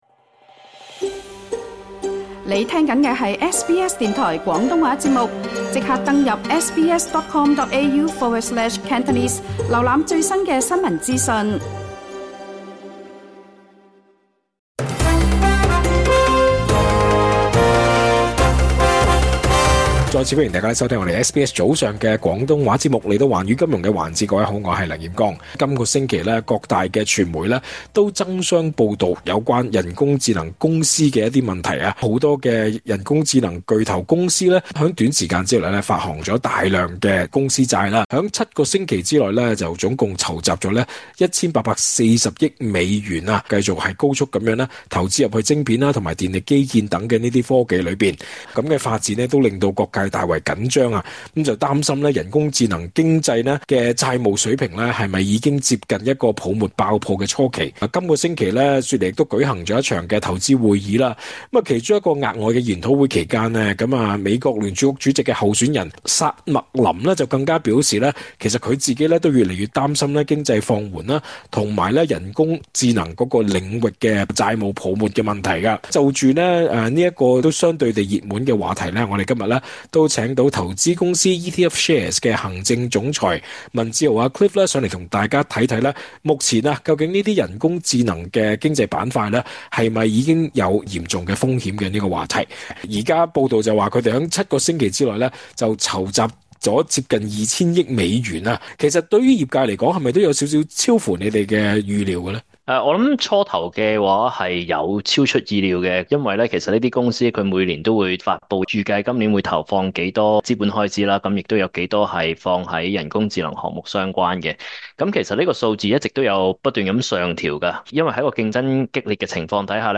向SBS廣東話【寰宇金融】節目表示，七周內發債二千億「超出預料」但並非「不能處理」。